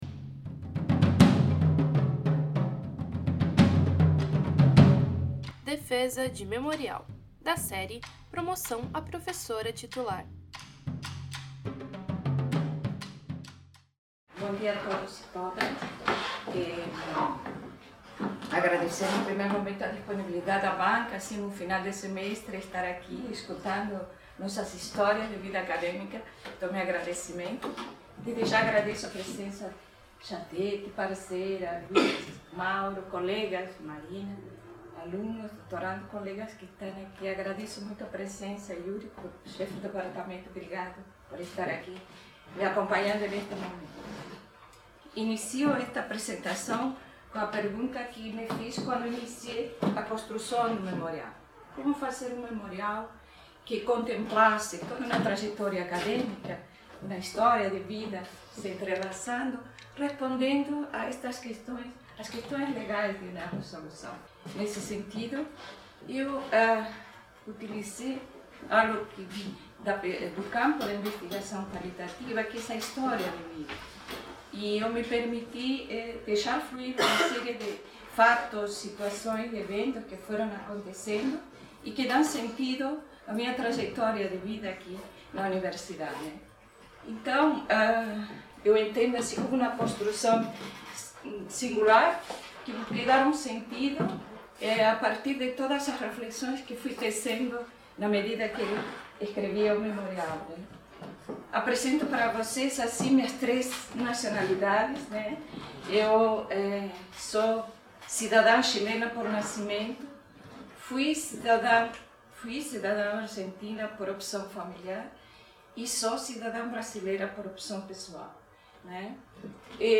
no Auditório do MArquE. Área de atuação: Psicologia da Saúde, Psicologia da Família e Comunitária.